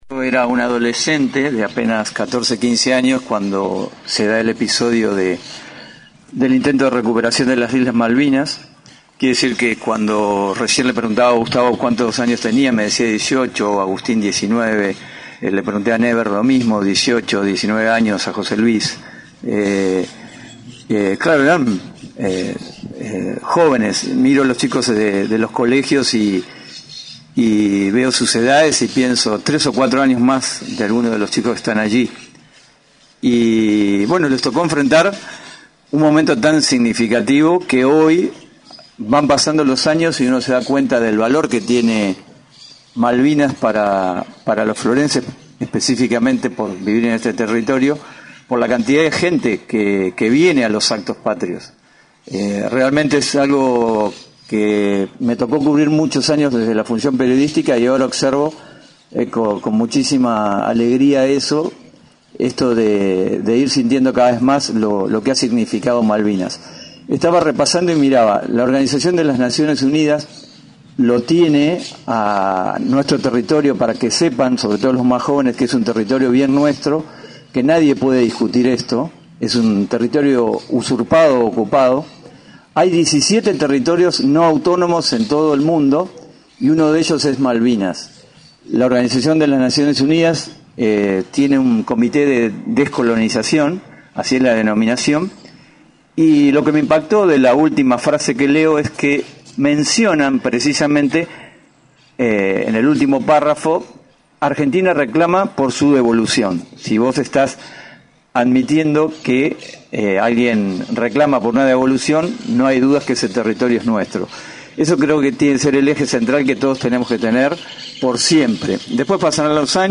Pasada las 11 de la mañana, se llevó a cabo en el nuevo monumento ubicado en Plaza Mitre el acto en conmemoración de los 41º años del desembarco en Malvinas.